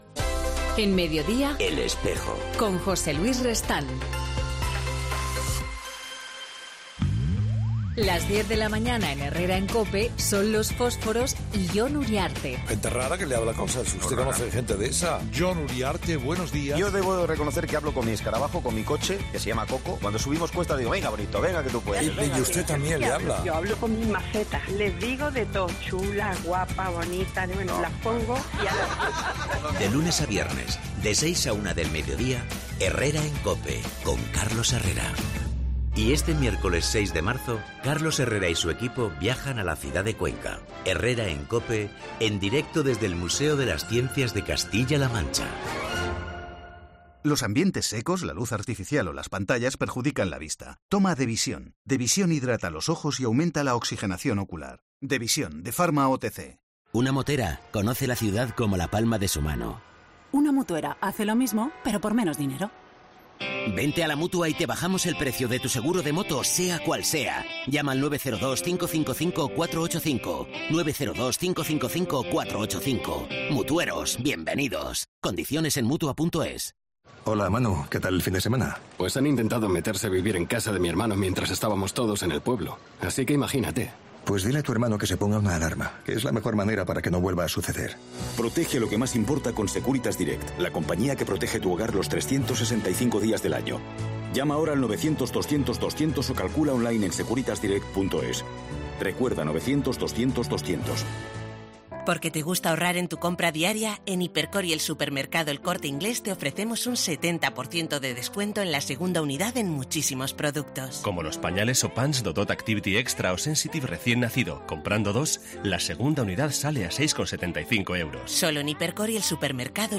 Hablamos con Mons. Jesús Sanz de la beatificación de nueve seminaristas mártires que tendrá lugar el próximo sábado en la catedral de Oviedo.